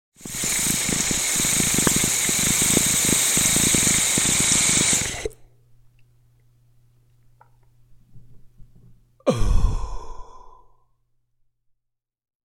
Парень затягивается, держит дым и медленно выпускает